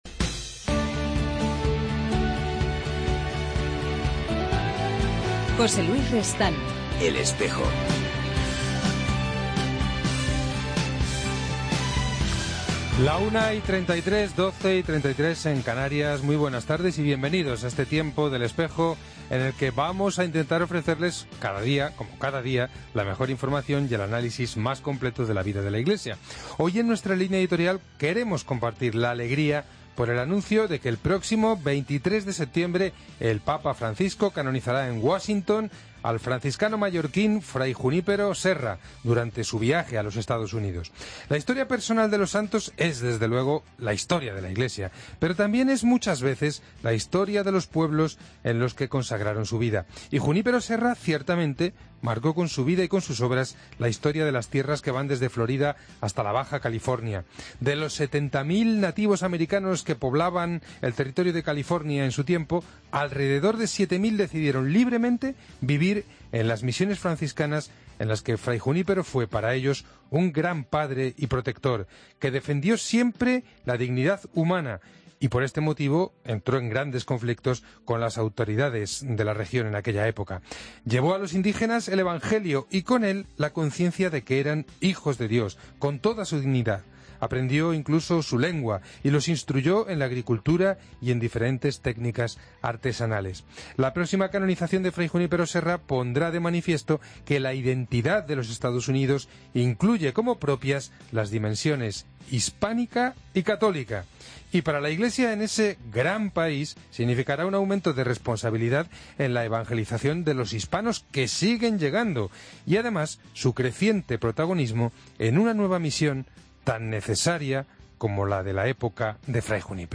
Hoy en El Espejo abordamos la celebración conjunta de la Jornada Mundial de Oración por las Vocaciones y la Jornada de las Vocaciones nativas, el domingo 26 de abril. están con nosotros Mons. Joan Enric Vives, Presidente de la Comisión de Seminarios de la CEE